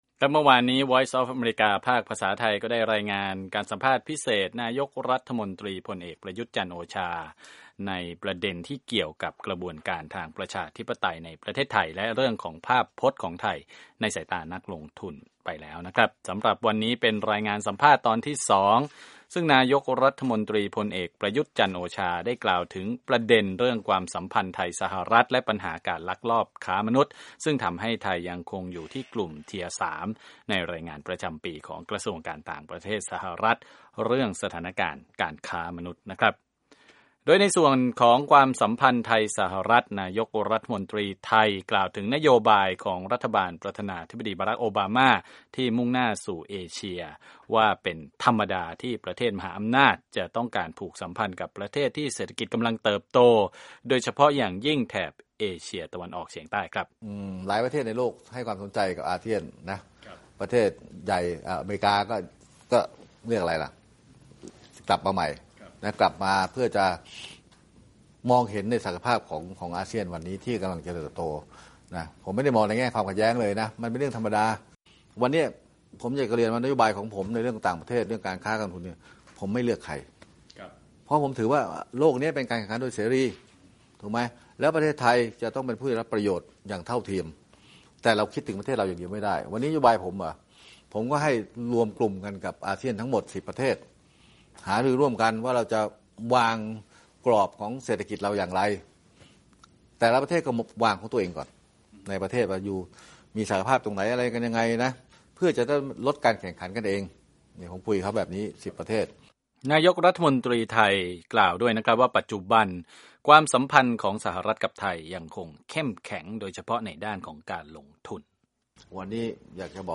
วีโอเอไทยสัมภาษณ์นายกฯ พลเอกประยุทธ์ จันทร์โอชา ระหว่างการเดินทางร่วมประชุมสมัชชาใหญ่สหประชาชาติที่นครนิวยอร์ค
สัมภาษณ์พิเศษนายกฯ พลเอกประยุทธ์ จันทร์โอชา (ตอน 2)
VOA Thai Interviews Thai Prime Minister